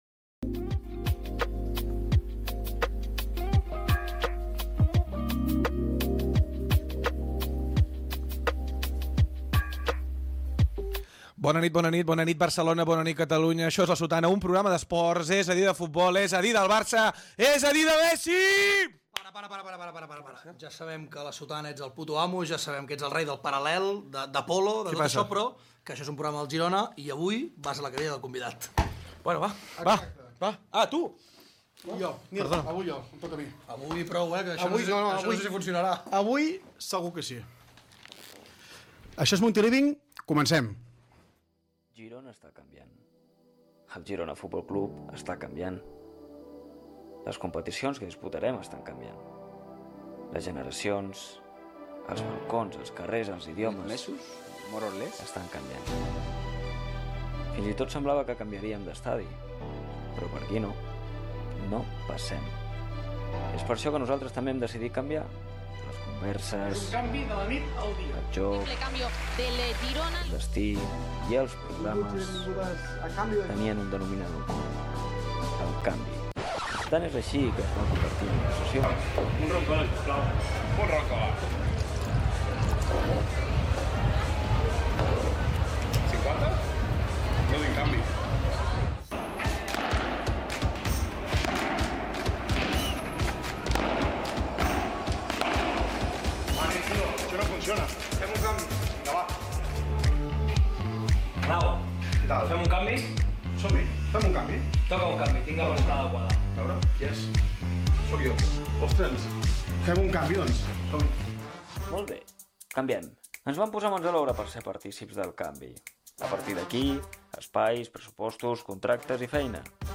Gènere radiofònic Esportiu